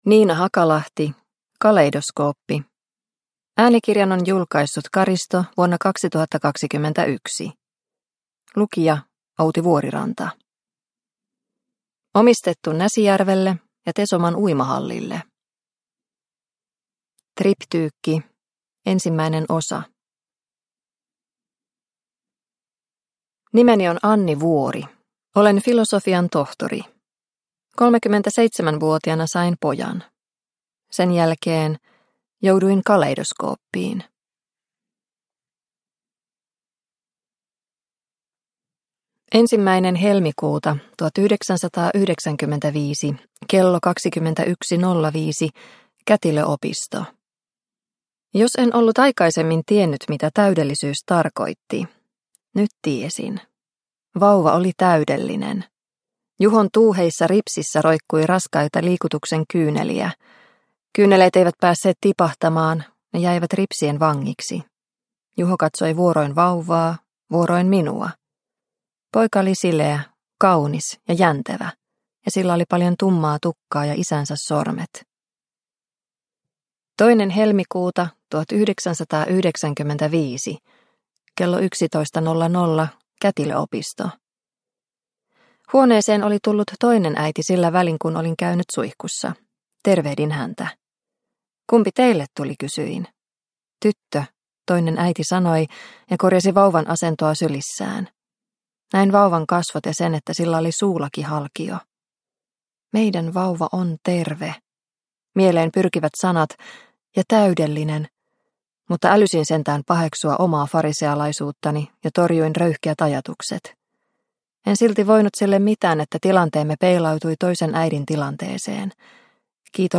Kaleidoskooppi – Ljudbok – Laddas ner